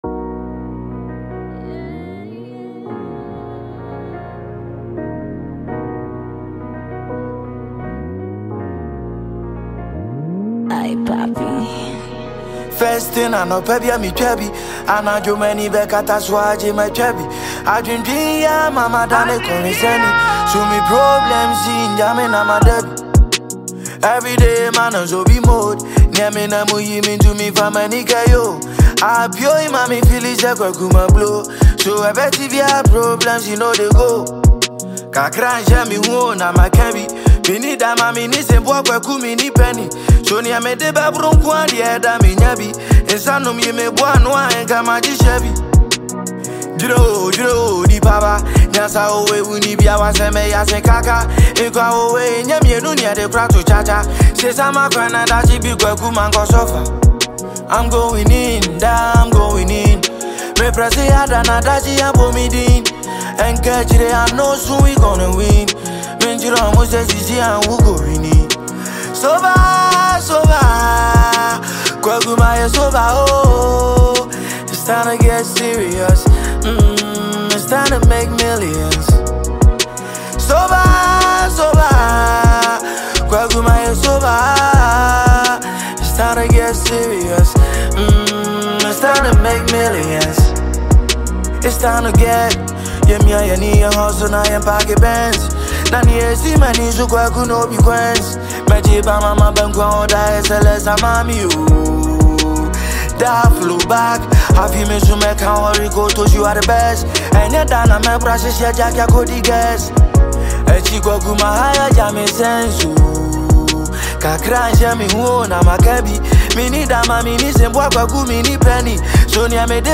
soulful anthem